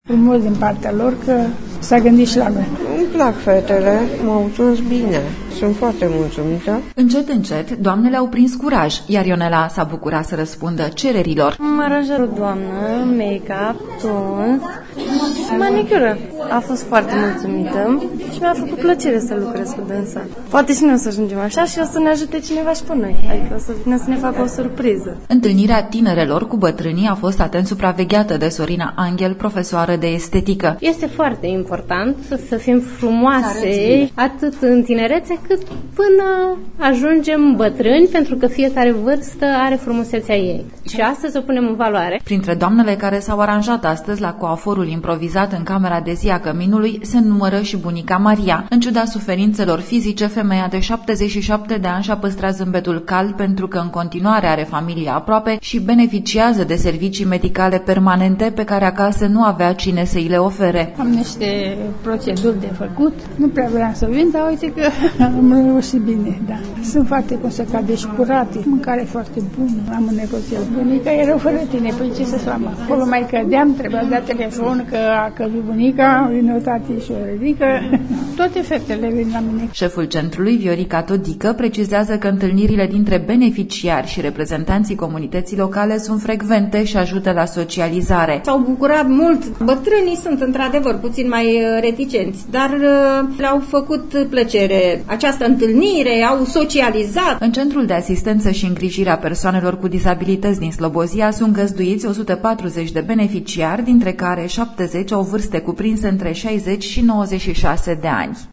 reportaj-servicii-de-estetica-pentru-varstnici.mp3